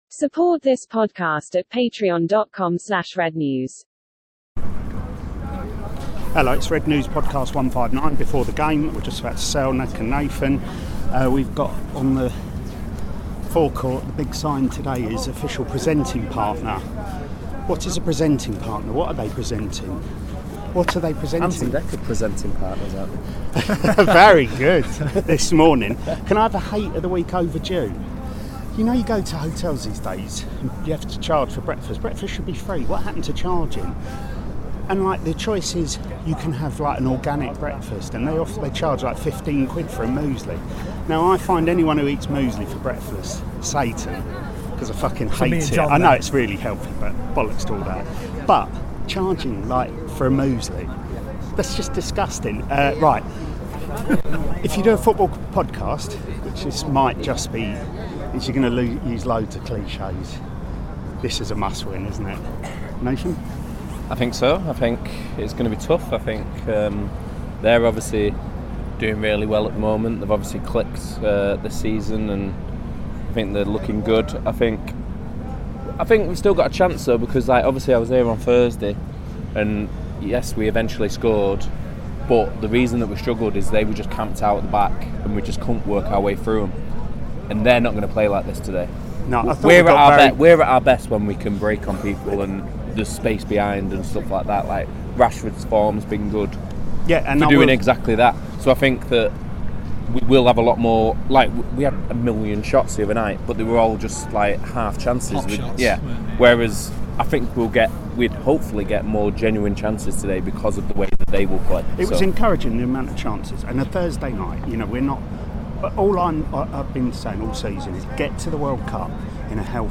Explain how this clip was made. The independent, satirical Manchester United supporters' fanzine - for adults only, contains expletives, talks MUFC, or not at times, as we talk before the draw with Newcastle and then a deeper chat back in the pub as Harry Styles gets a kick up the arse.